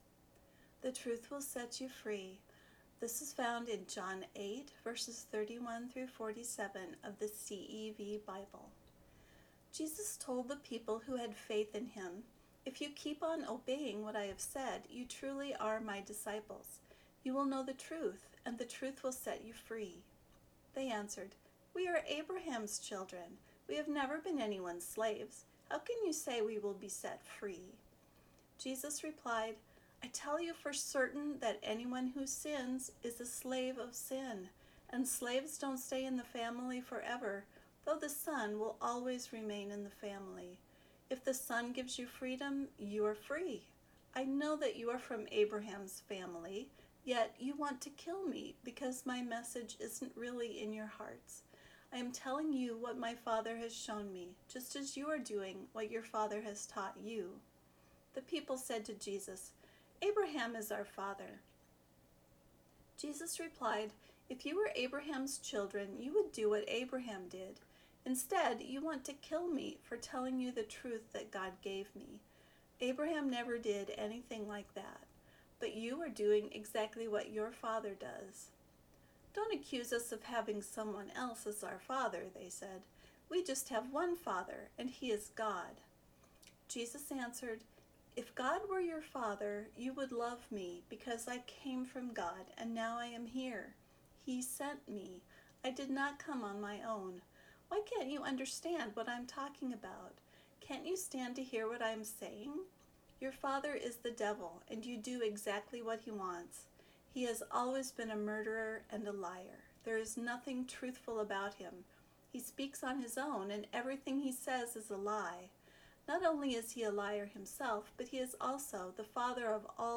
truth-will-set-you-free-faster-listening.mp3